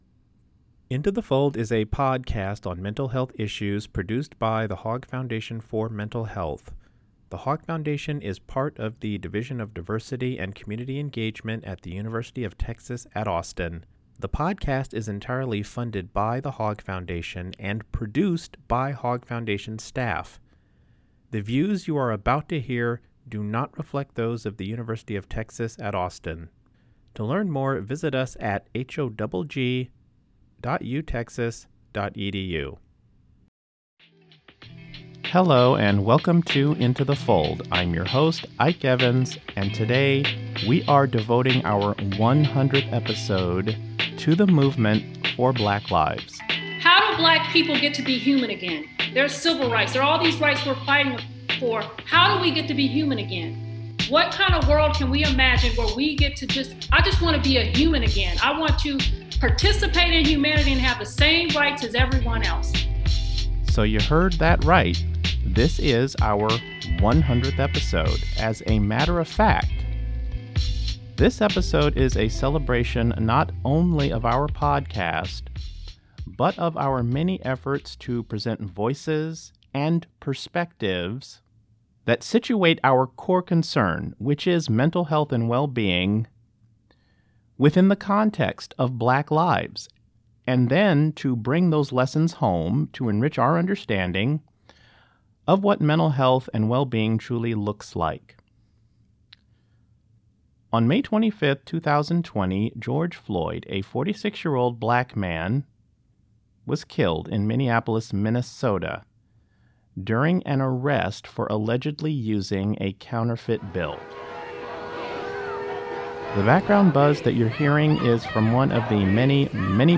In this milestone 100th episode of Into the Fold, we dive into the topic of racism and historical trauma with a panel of experts.